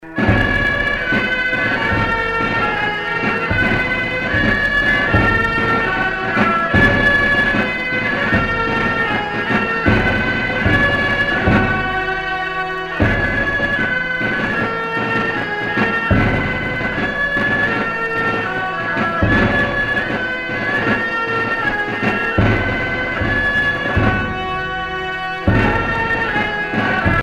Fonction d'après l'analyste gestuel : à marcher
Catégorie Pièce musicale éditée